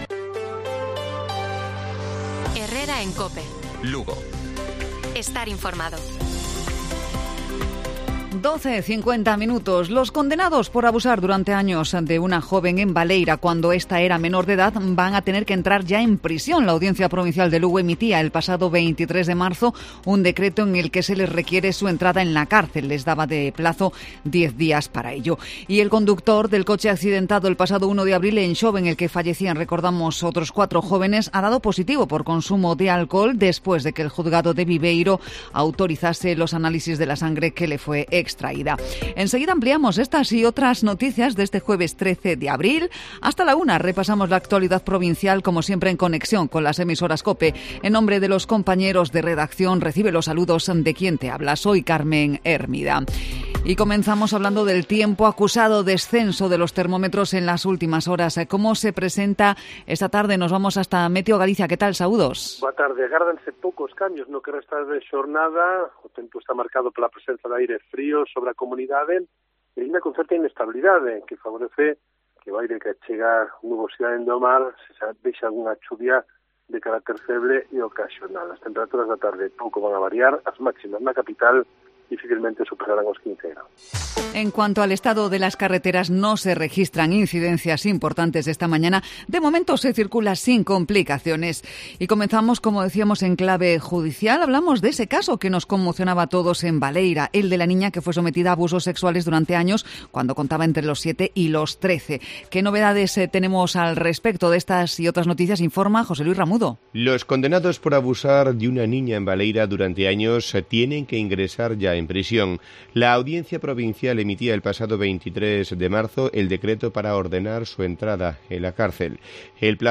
Informativo Provincial de Cope Lugo. 13 de abril. 12:50 horas